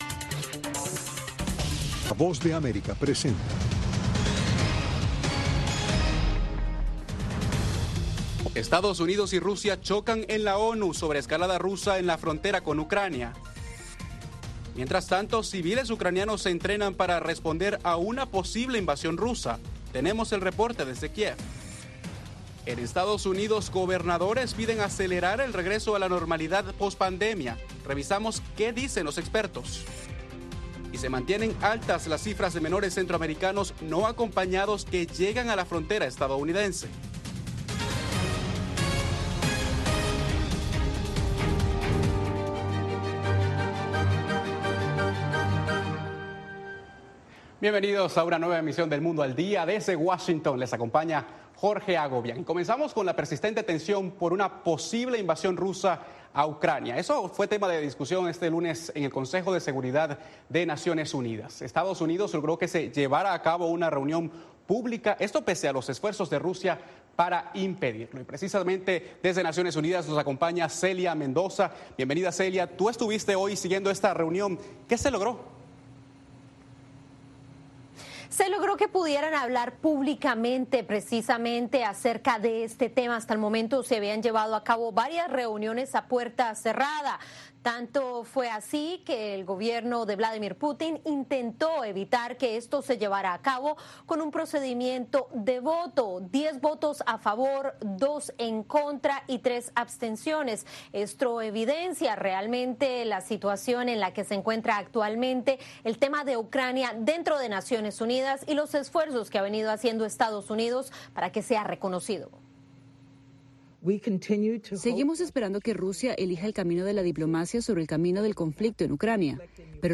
EE.UU. y Rusia chocan en la ONU sobre la escalada rusa en la frontera con Ucrania. Mientras tanto, civiles ucranianos se entrenan para responder a una posible invasión rusa Tenemos el reporte desde Kiev.